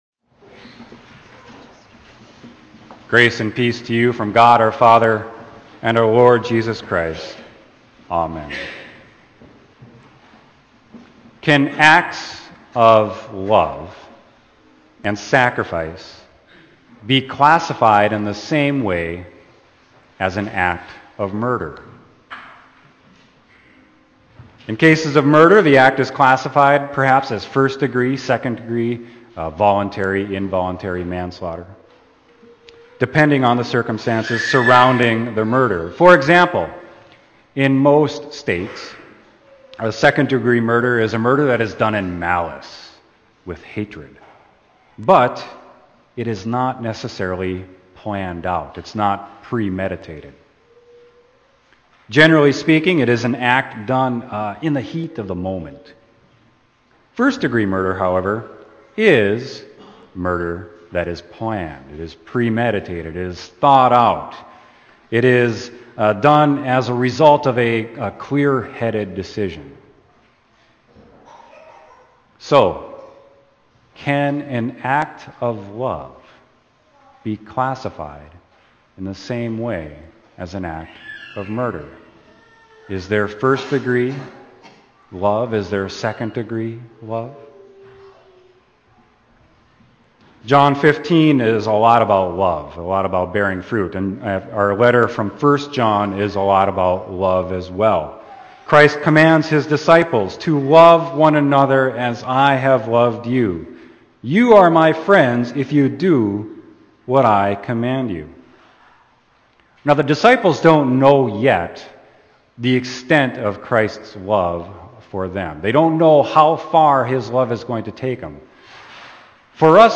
Sermon: John 15.9-17